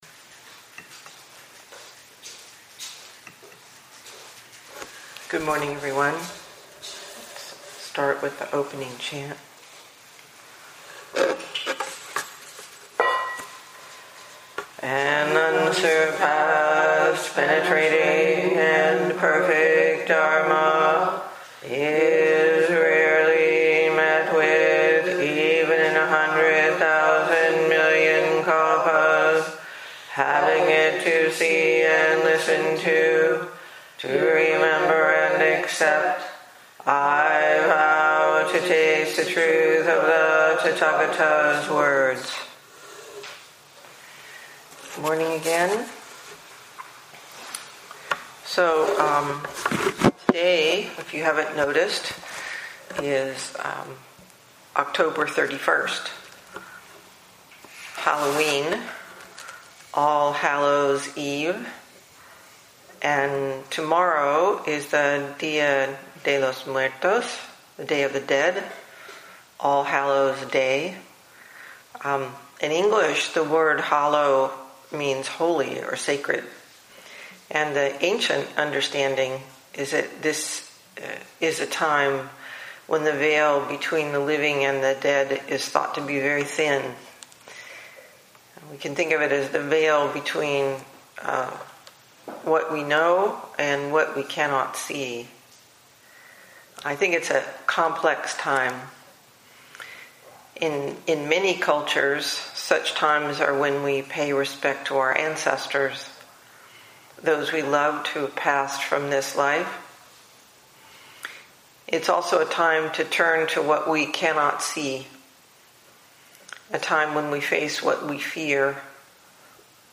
This lecture can also be watched on YouTube at our channel: Ocean Gate Zen Center